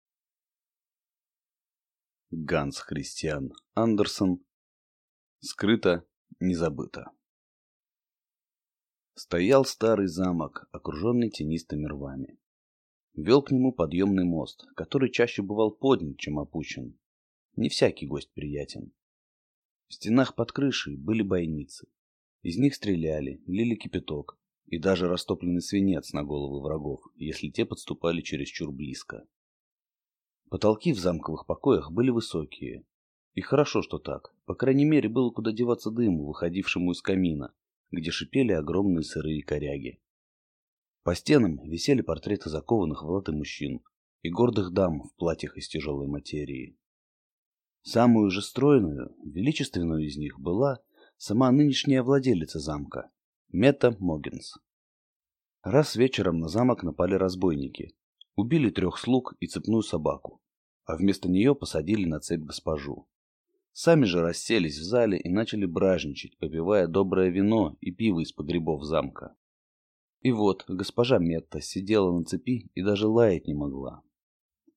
Аудиокнига Скрыто – не забыто | Библиотека аудиокниг
Прослушать и бесплатно скачать фрагмент аудиокниги